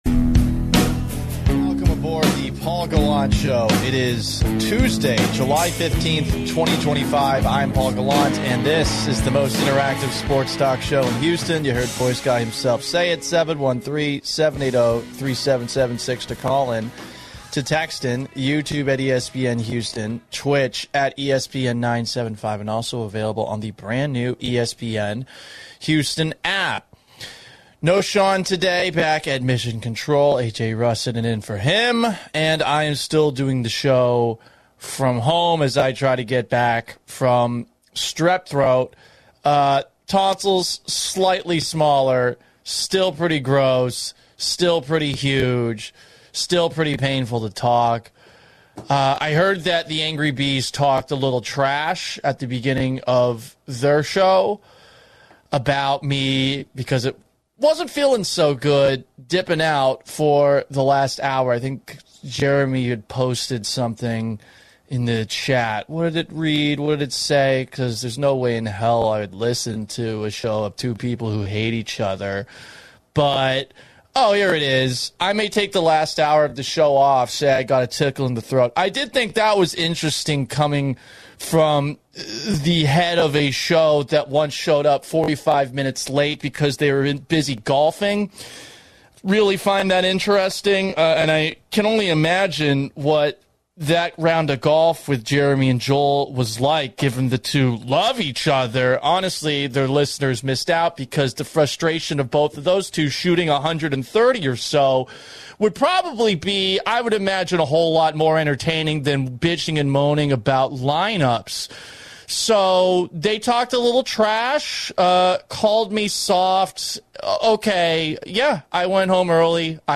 in-house studio